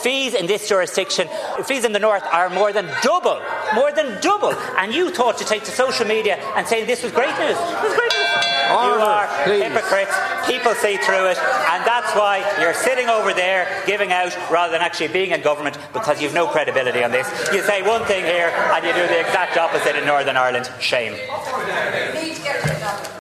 There were clashes in the Dail this between Deputy Pearse Doherty and Tanaiste Simon Harris afternoon after the Donegal TD and Sinn Fein Finance Spokesperson accused the government of raising student fees.